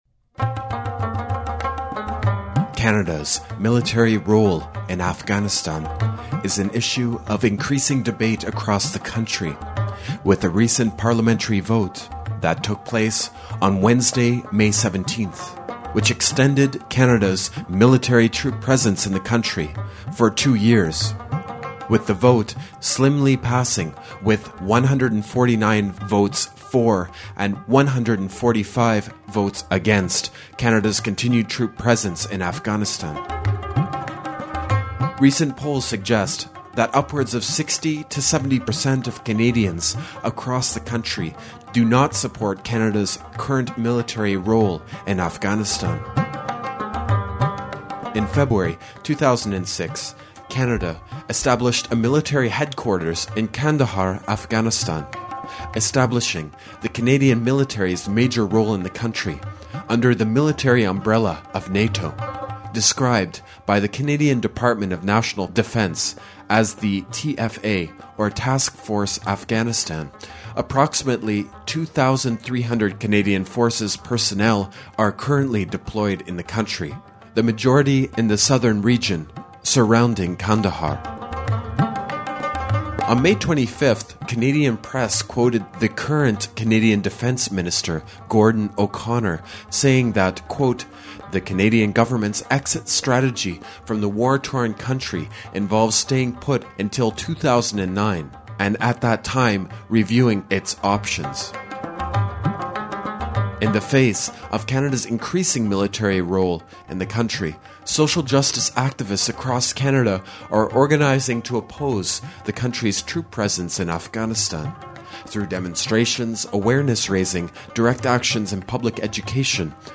This presentation was given in Montreal in April 2006, at an event organized by CKUT Radio's Community News Collective concerning media in a time of war, within the context of Canada's growing military role within Afghanistan.